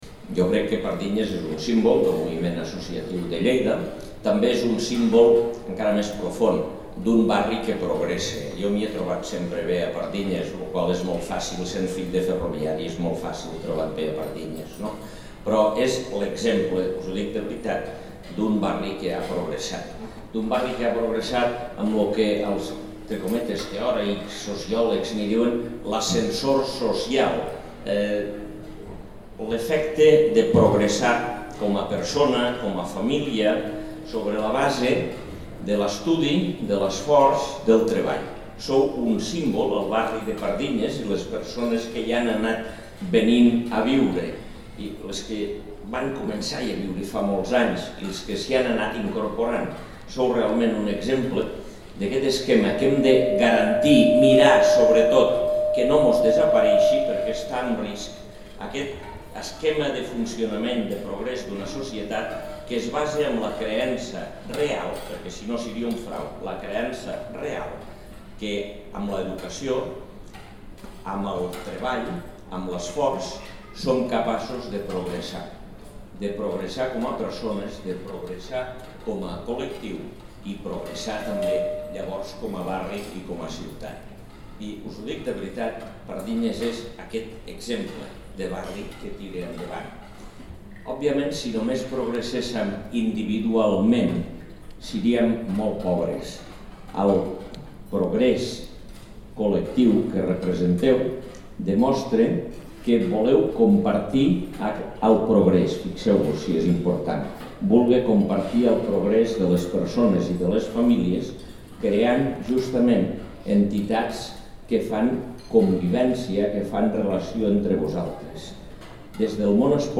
Tall de veu de l'alcalde, Àngel Ros, sobre la cloenda dels actes del 50è aniversari d'Orvepard (3.3 MB) Fotografia 1 amb major resolució (2.3 MB) Fotografia 2 amb major resolució (1.9 MB) Fotografia 3 amb major resolució (2.1 MB)
tall-de-veu-de-lalcalde-angel-ros-sobre-la-cloenda-dels-actes-del-50e-aniversari-dorvepard